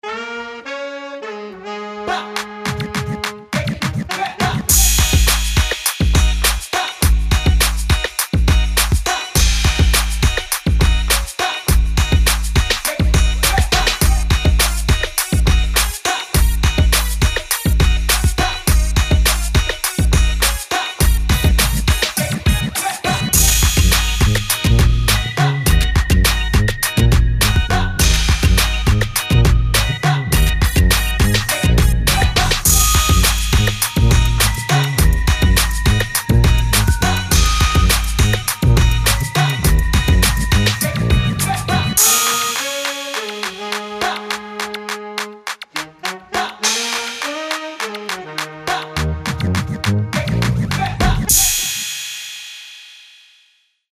New Orleans bounce sounds
Feel the brass, catch the bounce
tuba
trumpet
alto sax
tenor sax
trombone